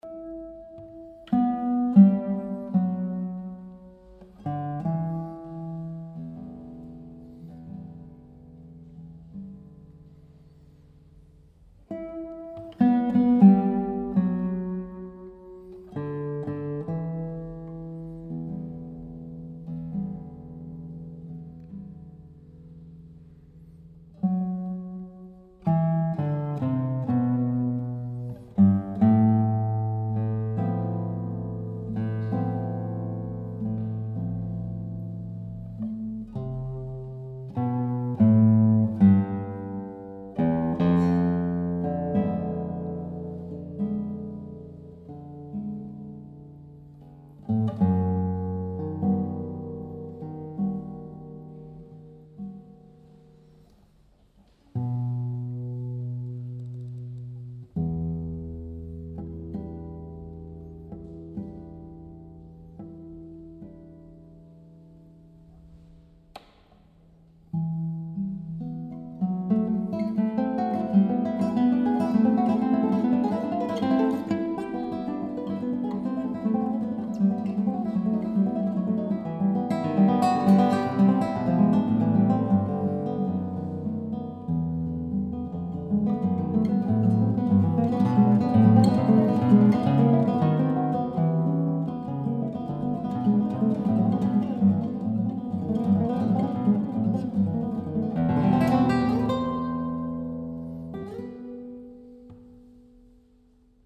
Roland Dyens用Burlot吉他演奏的音频, recorded on May, 16th 2009 in Germany：